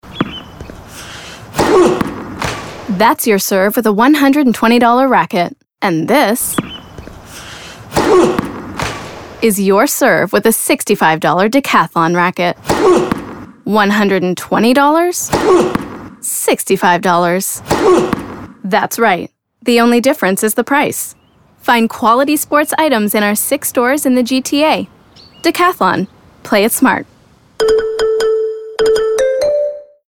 Commercial (Decathlon) - EN